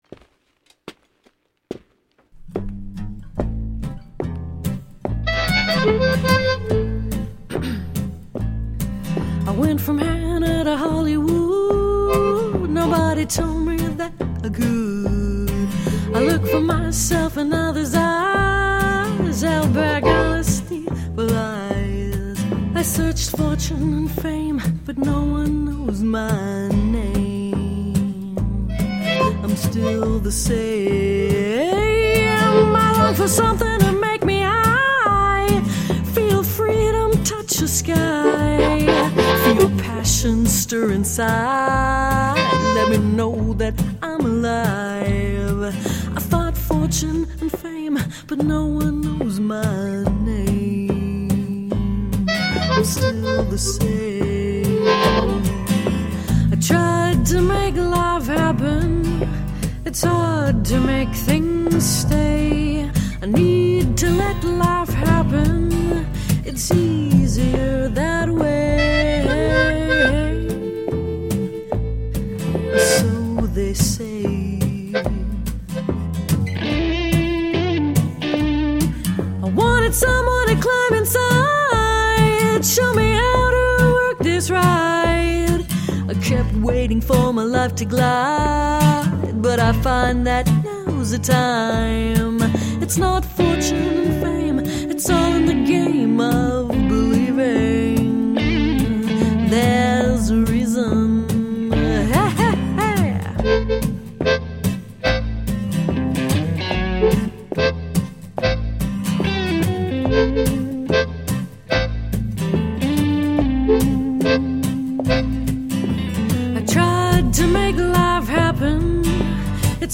Jazzed up, funk-tinged eclectic pop..
Tagged as: Alt Rock, Rock, Ironic Rock